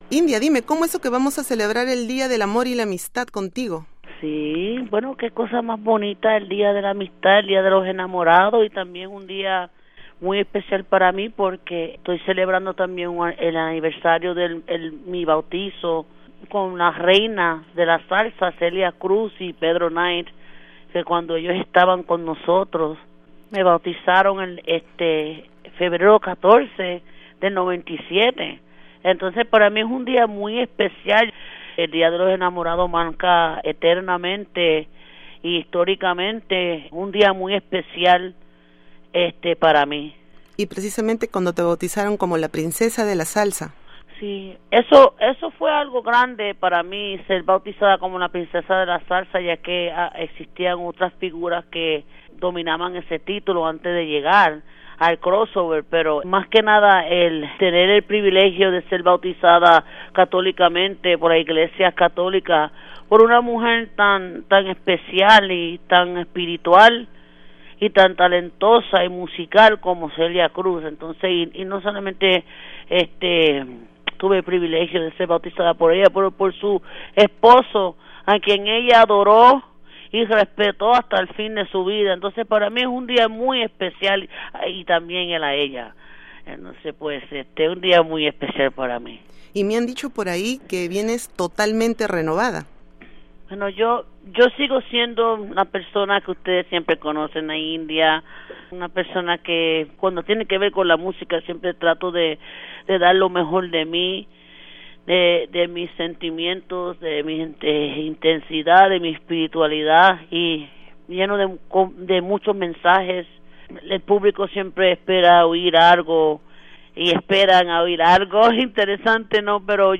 Entrevista a La India